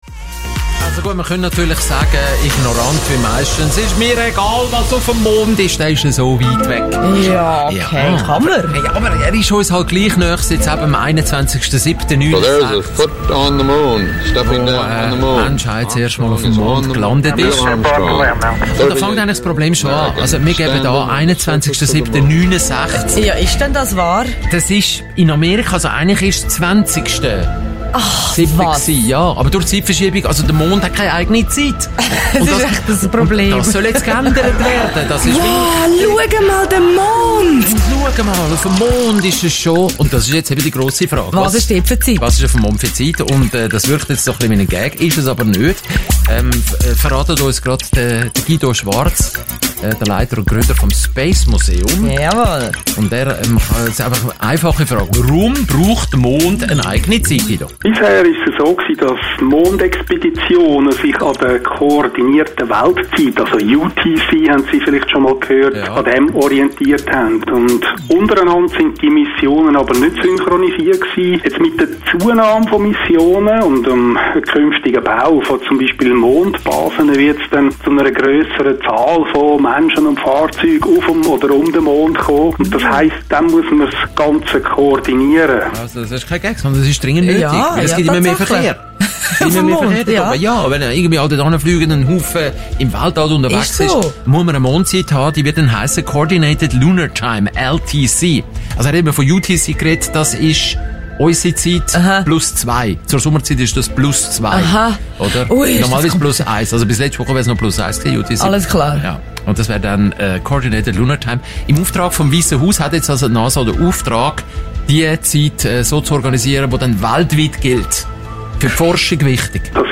Radiobeitrag Teil 1